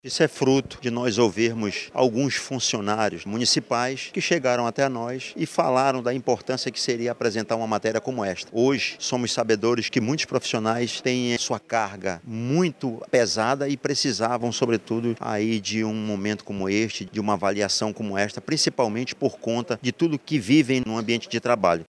O vereador Everton Assis, União Brasil, autor da PL que institui a Política de Atenção à Saúde Mental de profissionais da saúde pública, explicou que a proposta é fruto de escuta.